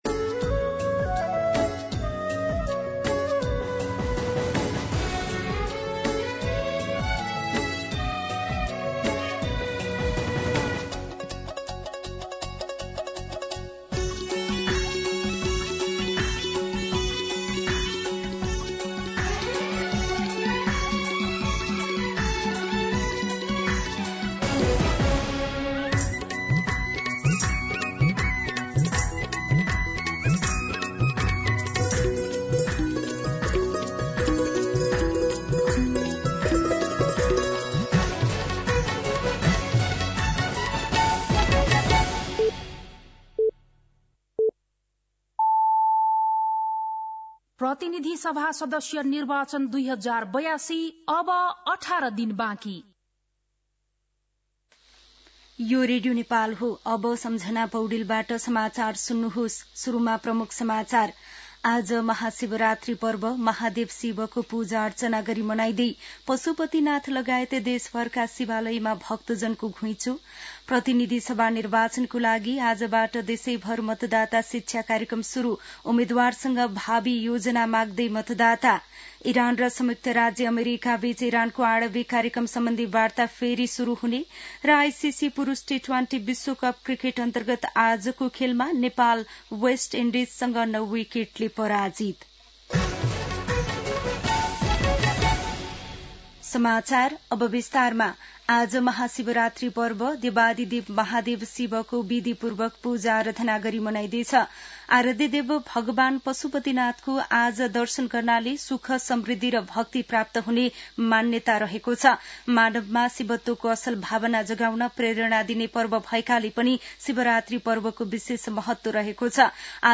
An online outlet of Nepal's national radio broadcaster
दिउँसो ३ बजेको नेपाली समाचार : ३ फागुन , २०८२
3pm-News-11-03.mp3